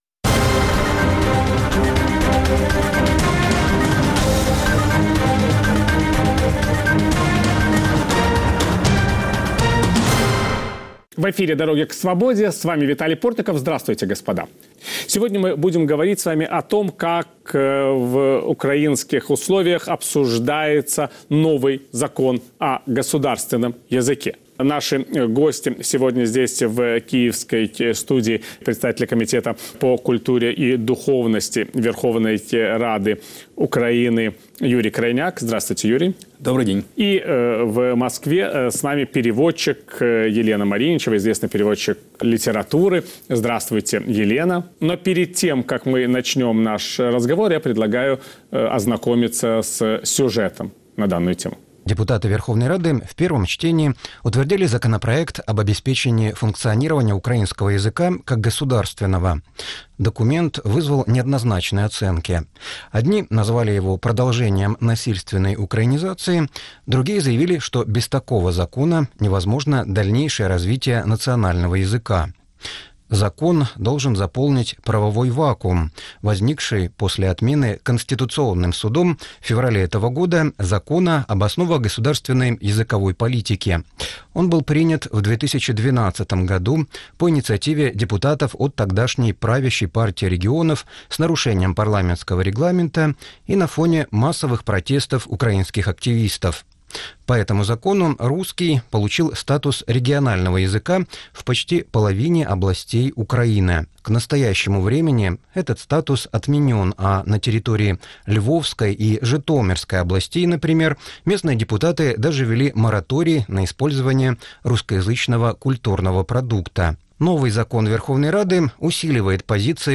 Каким будет новый закон об украинском государственном языке, который сейчас обсуждает парламент? Насколько обоснованы опасения его критиков? Виталий Портников беседует с одним из авторов закона